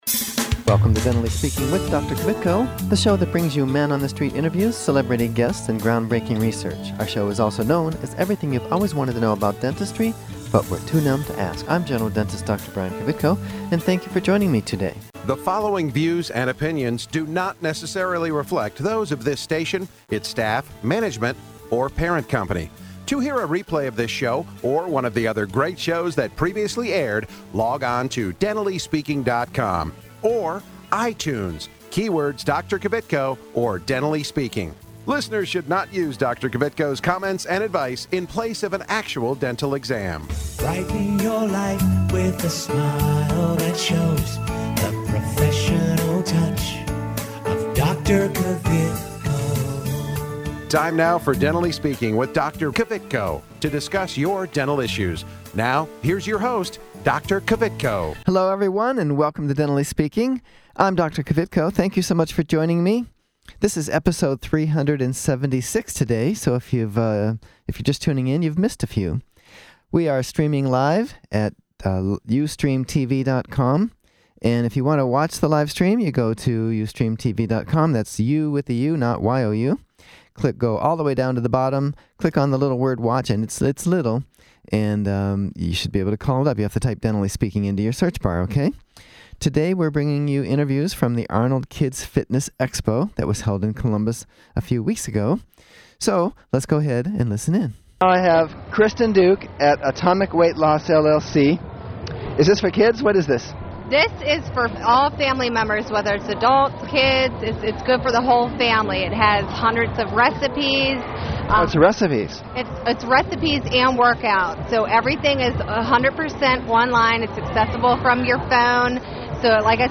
Interviews From The Arnold Kids Fitness Expo